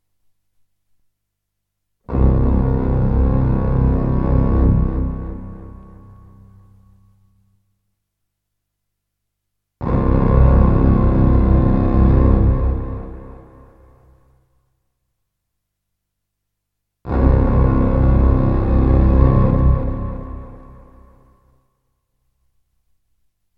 Octave Electric violin foghorn